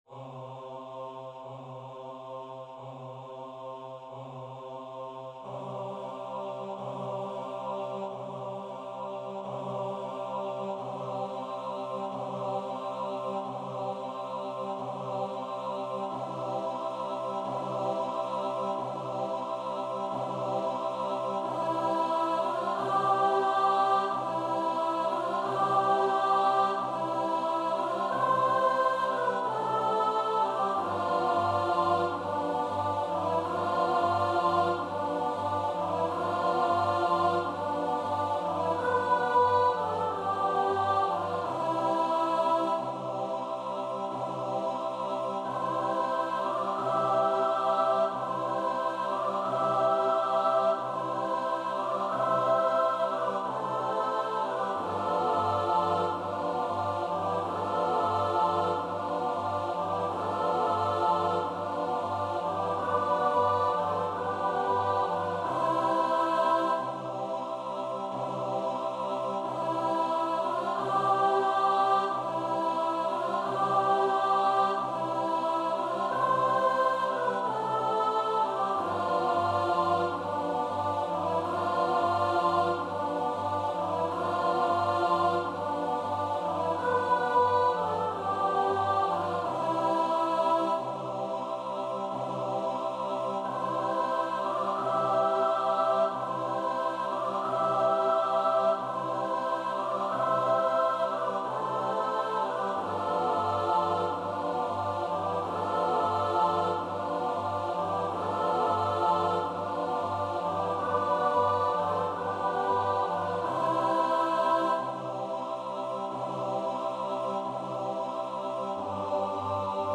Free Sheet music for Choir (SATB)
C major (Sounding Pitch) (View more C major Music for Choir )
Slowly, inevitably = c.45
2/4 (View more 2/4 Music)
Choir  (View more Easy Choir Music)
Classical (View more Classical Choir Music)
Austrian